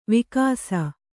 ♪ vikāsa